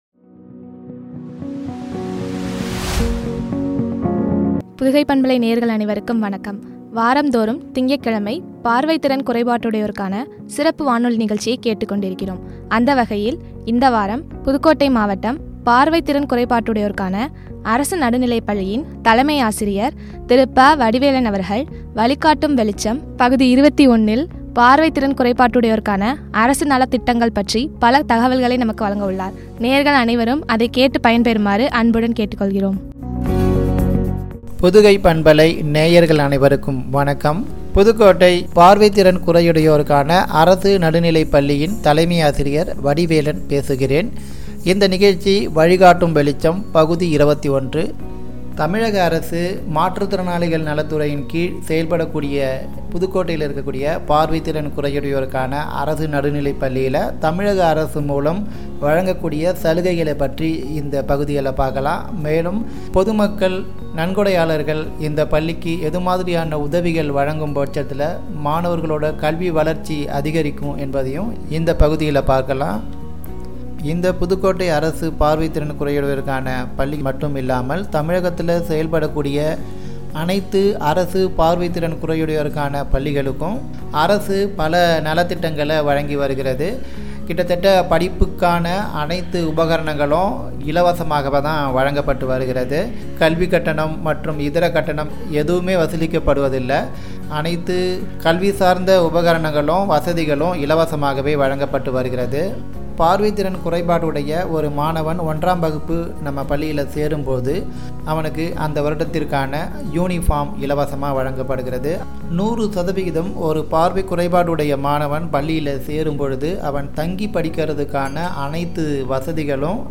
பார்வை திறன் குறையுடையோருக்கான சிறப்பு வானொலி நிகழ்ச்சி
” (பார்வைத்திறன் குறையுடையோருக்கான அரசு நலத்திட்டங்கள்), குறித்து வழங்கிய உரையாடல்.